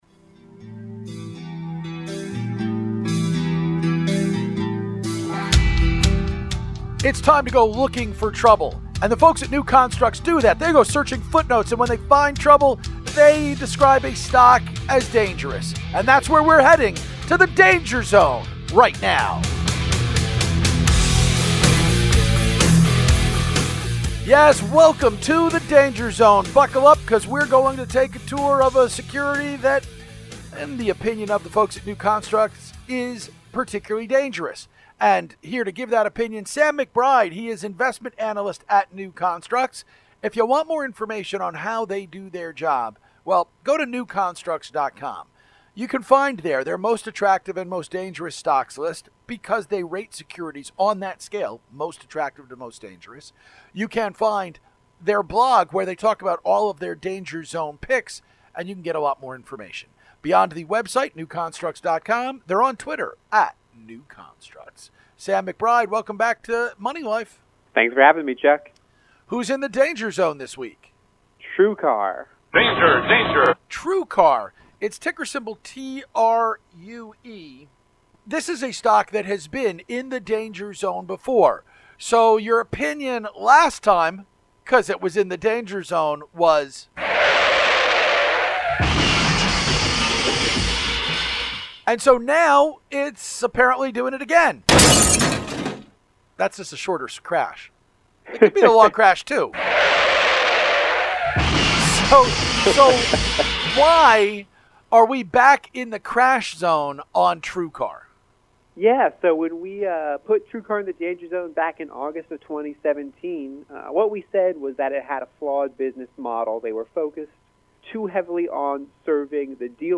Danger Zone interview